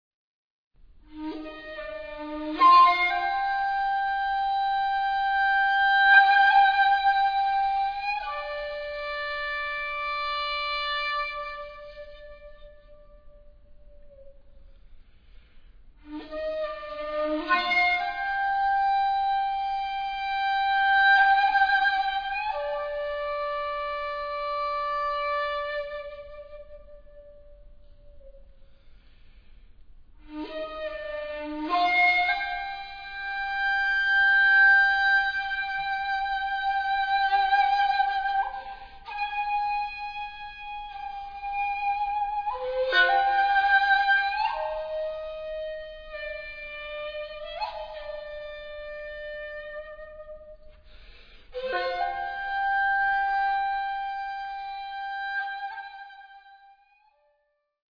World
Avant-garde